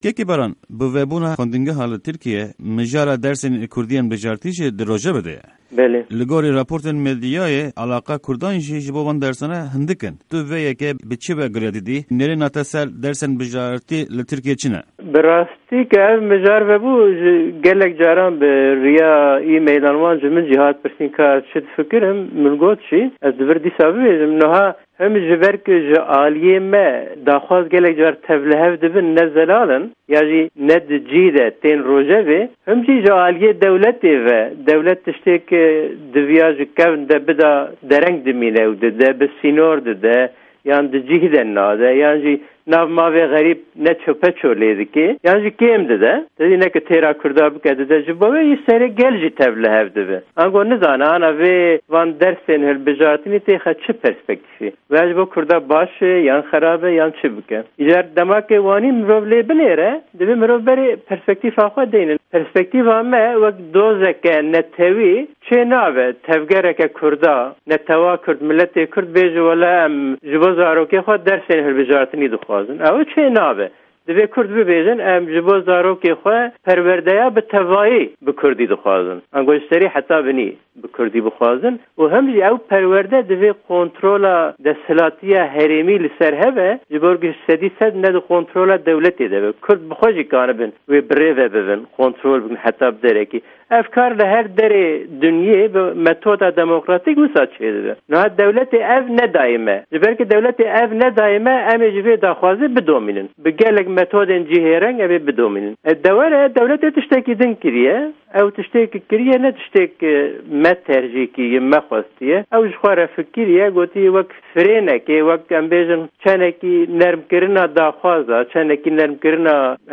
Zimanzan